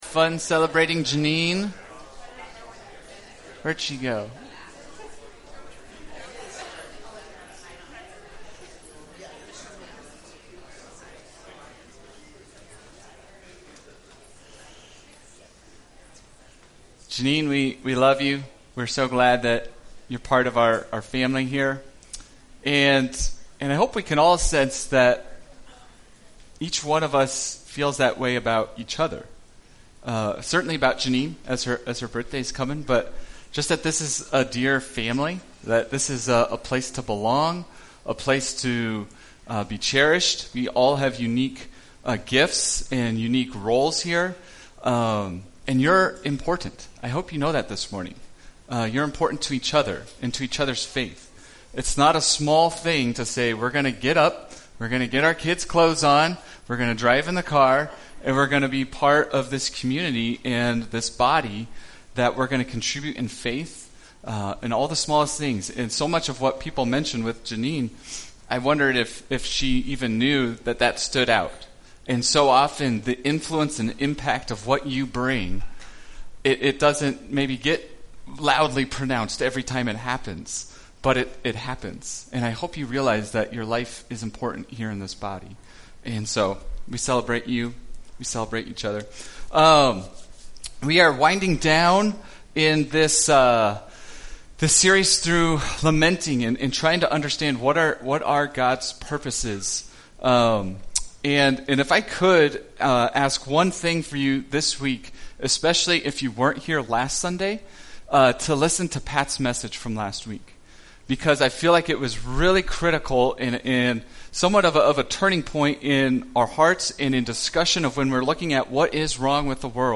Lamentations Service Type: Sunday Speaker